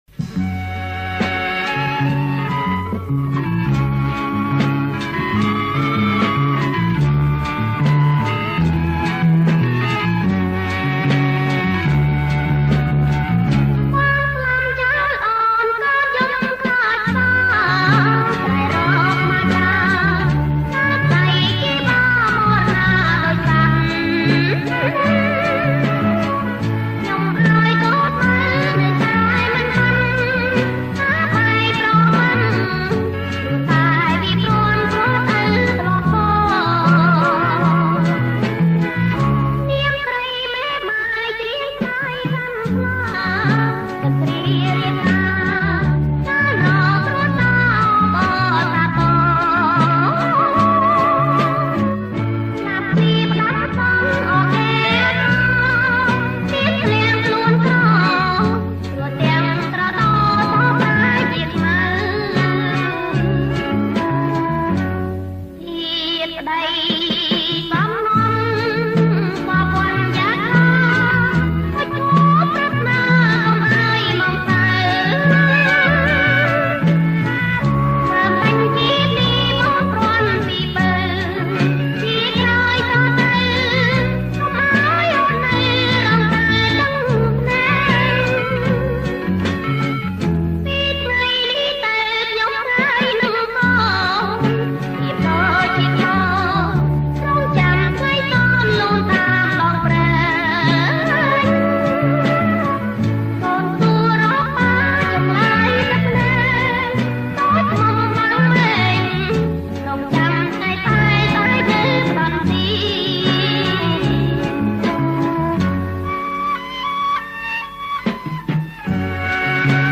• ប្រគំជាចង្វាក់ Bolero Folk